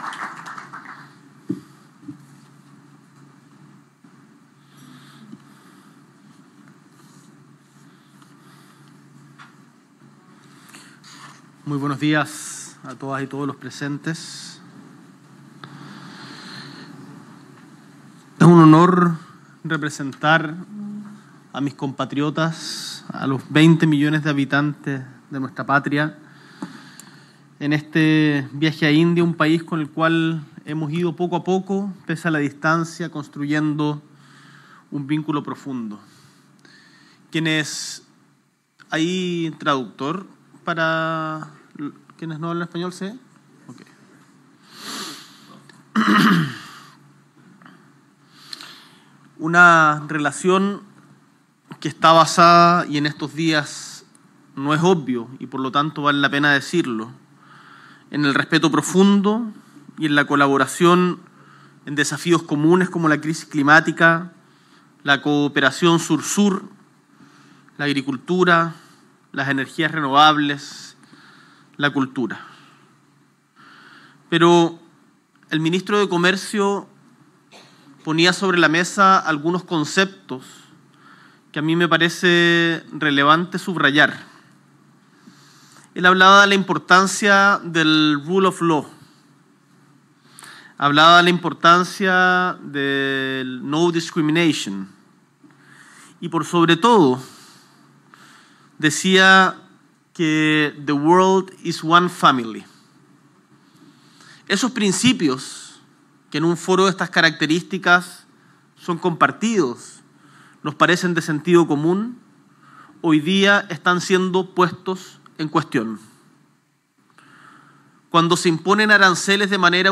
S.E. el Presidente de la República, Gabriel Boric Font, encabeza encuentro Empresarial Chile-India